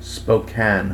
En-us-Spokane.ogg